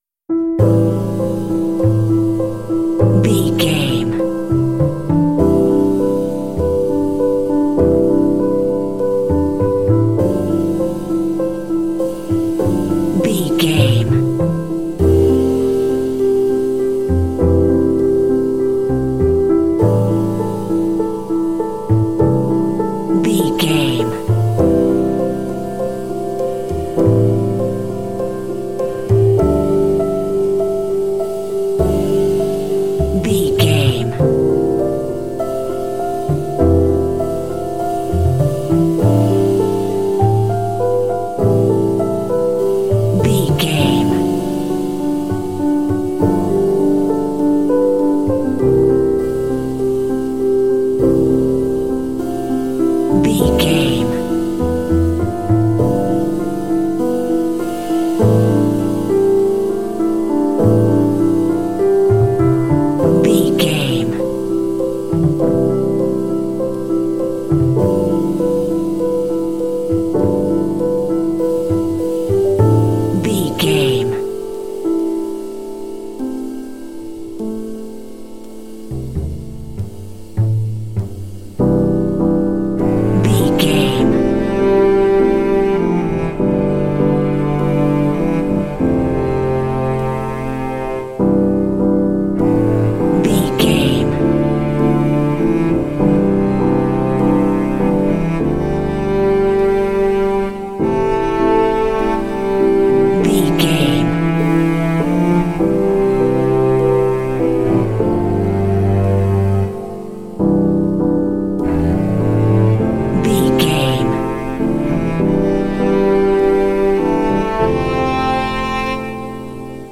Aeolian/Minor
mellow
melancholy
mournful
piano
cello
percussion
modern jazz